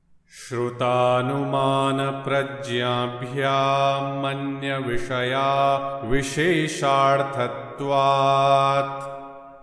Yoga Sutra 1.49 | Śhrutānumāna-prajnābhyām anya-v...| Chant Sutra 1.49